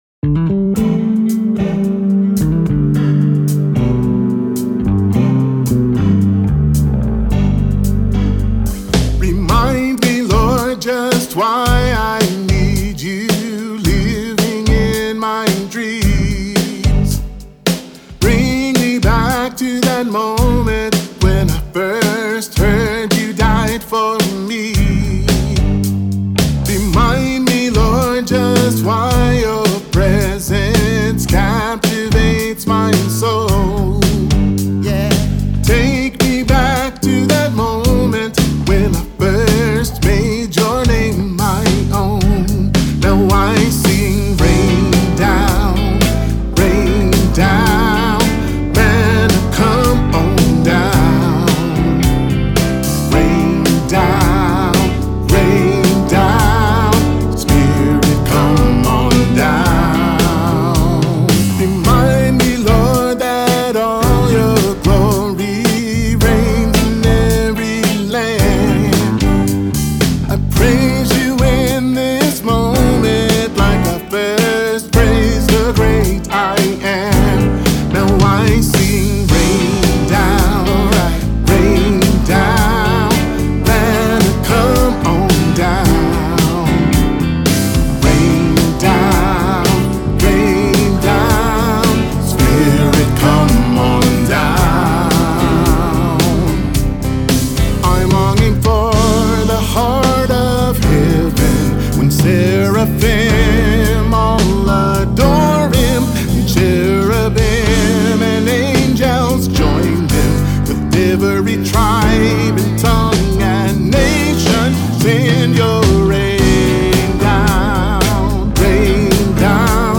Genre – Christian/Gospel